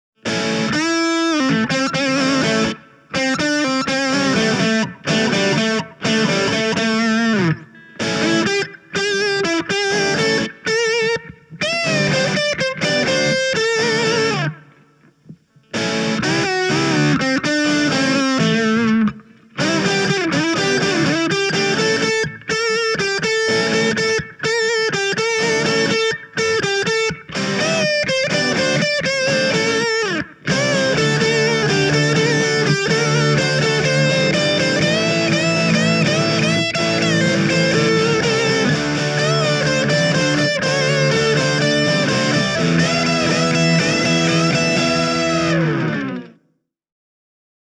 ROCK demo
Rhythm guitars: Fender Telecaster (left channel) & Gibson Les Paul Junior (right channel)
Lead guitar: Gibson Melody Maker SG